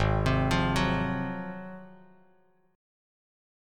G#13 chord